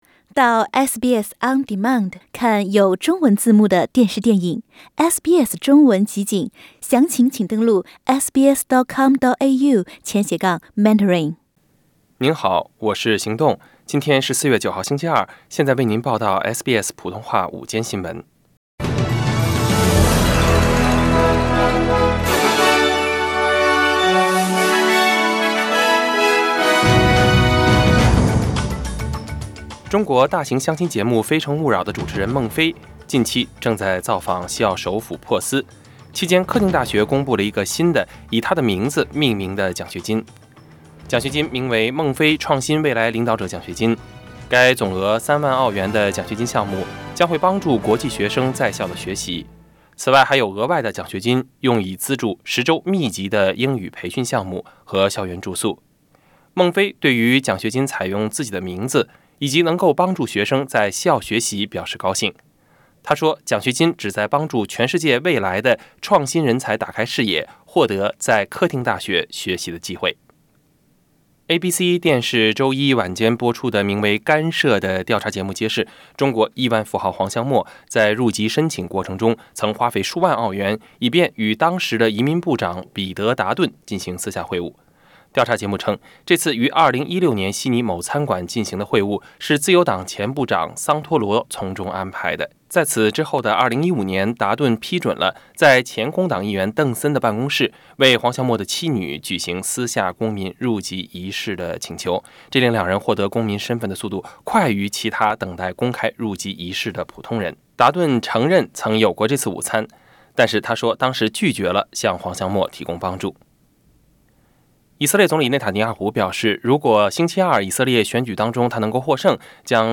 SBS午間新聞（4月9日）